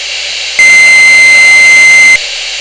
This unit has an electronic tone whistle which is ideal for a quick tot tot.
The Whistle
mtc_whistle.wav